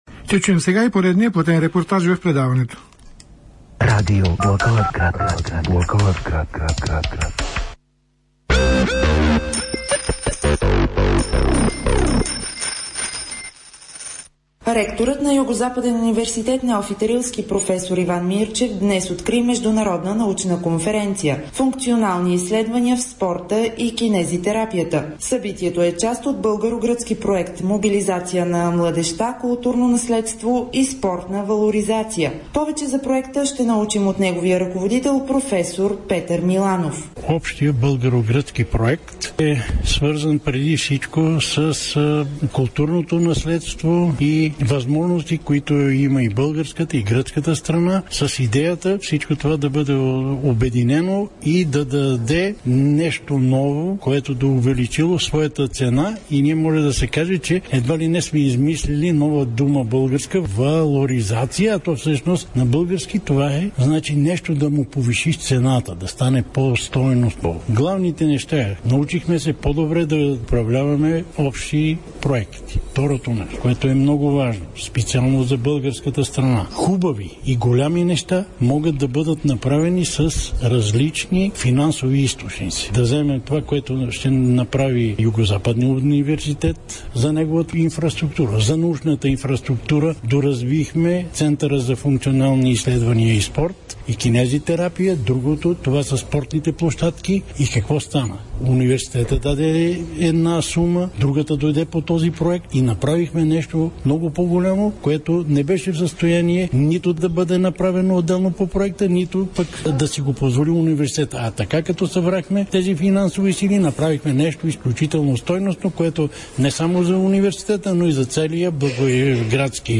reportaj.mp3